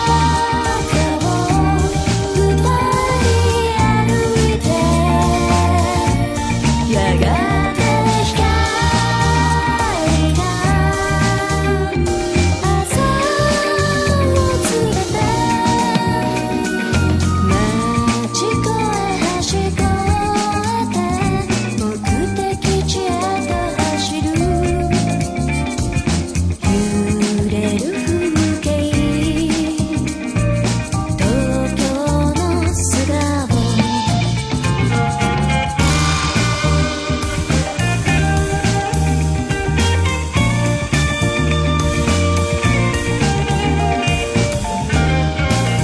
70年代ニューミュージックの再構築プロジェクト